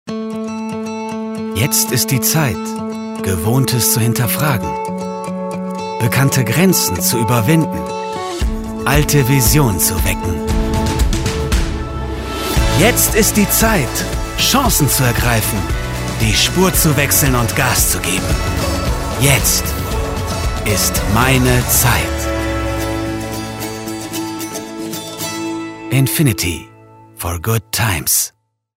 Jung - dynamisch - voll - warm - variable
Sprechprobe: Industrie (Muttersprache):
dynamic - full - warm